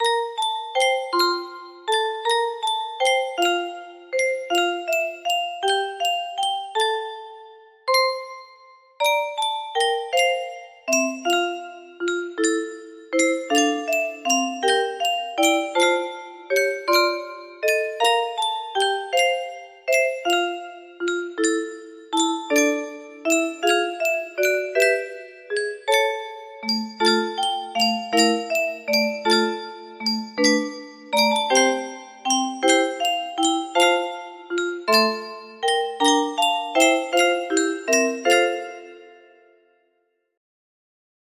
O gwiazdo Betlejemska music box melody
Arrangement of Polish Christmas carol.